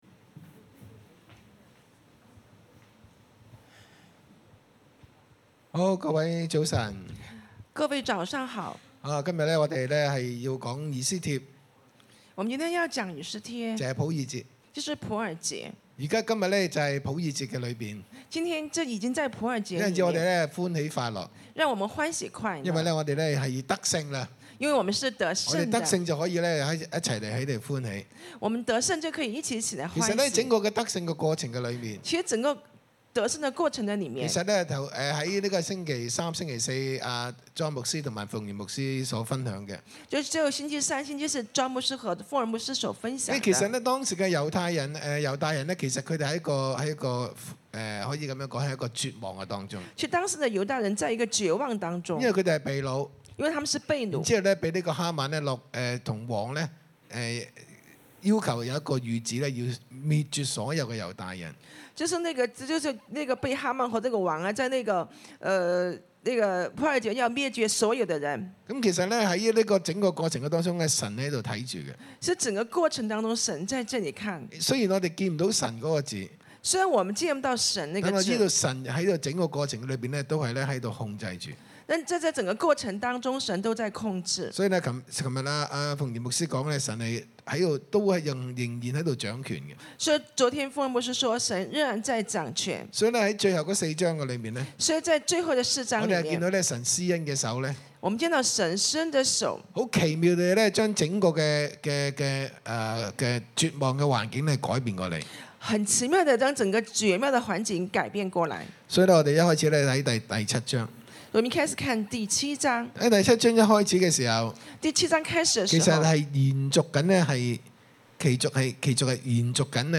方言禱告。